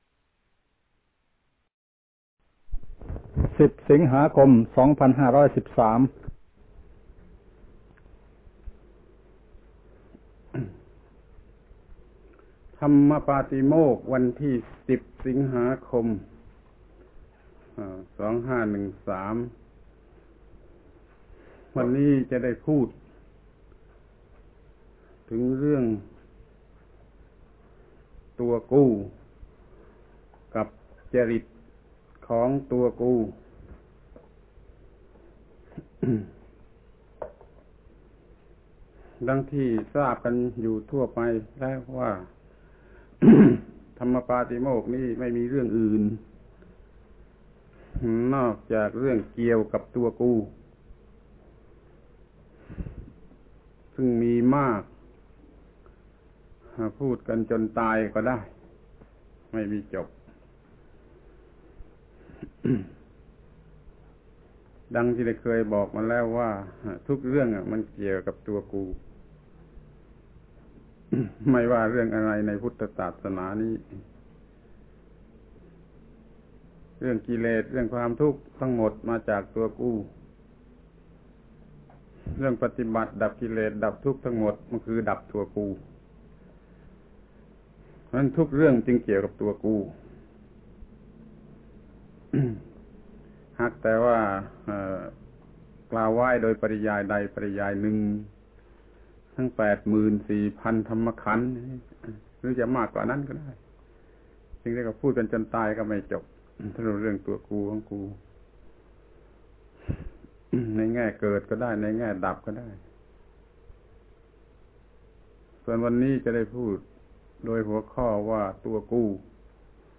พระธรรมโกศาจารย์ (พุทธทาสภิกขุ) - อบรมพระที่หน้าโรงหนัง เรื่อง โอวาทปาฏิโมกข์ ปี 2513 ครั้งที่21/ตัวกูกับจริตของตัวกู